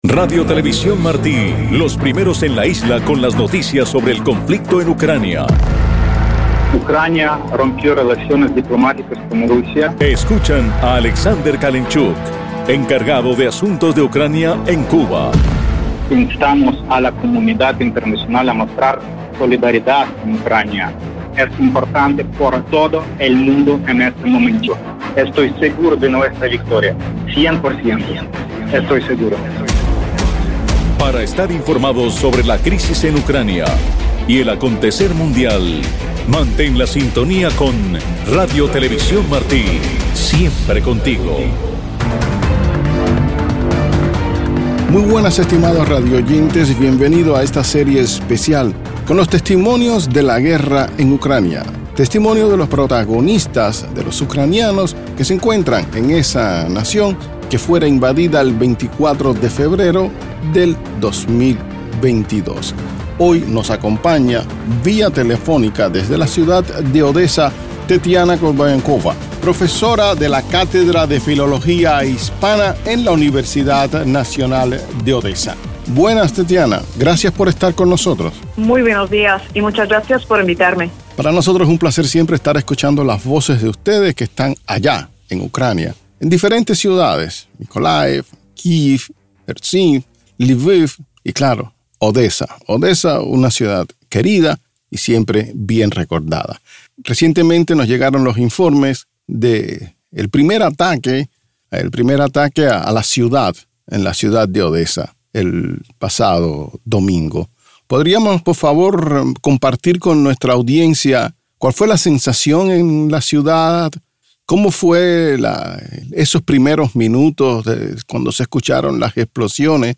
Testimonios de la Guerra en Ucrania: entrevista